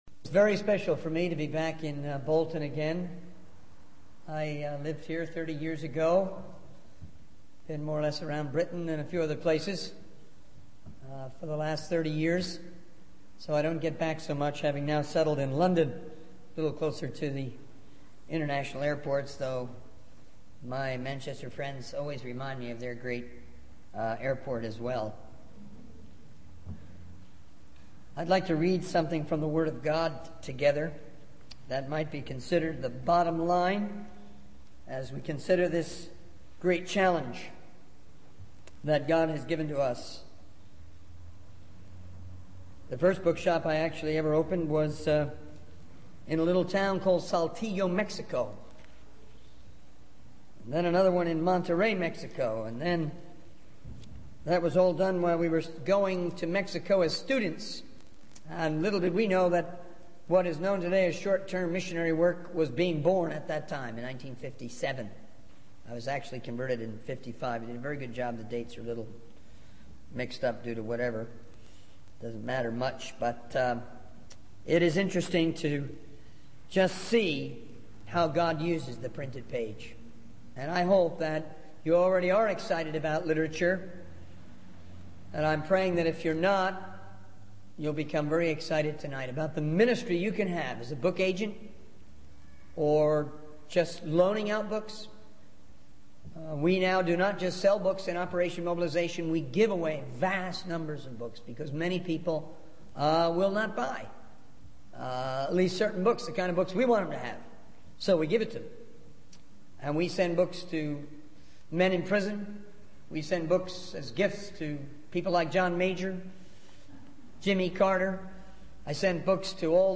In this sermon, the preacher focuses on the importance of preaching the word of God and spreading the message of Jesus Christ. He emphasizes the need for believers to be sent out and to share the good news with others.